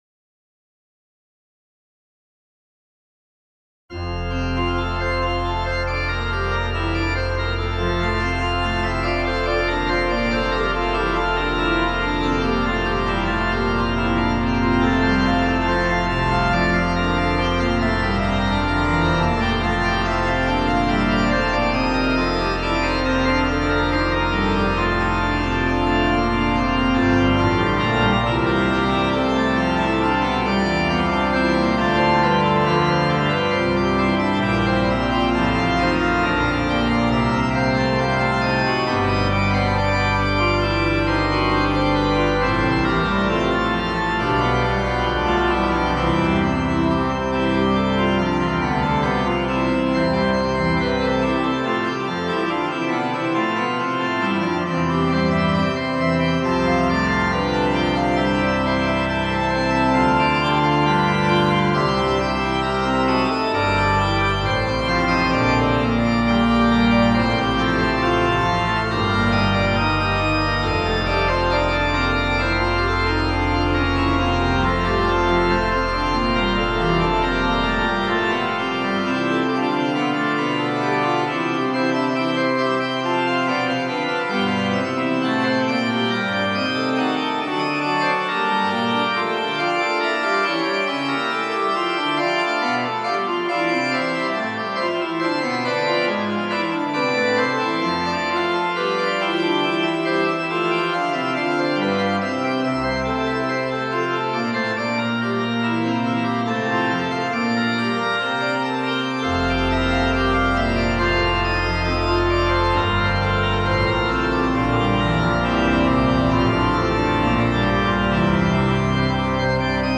Testbericht – Organteq Kirchenorgel von Modartt
Für die Klangdateien wurden MIDI Files von Viscount Organs UK verwendet. Bei den Einstellungen wurde 1 Keyboard und All Stops gewählt.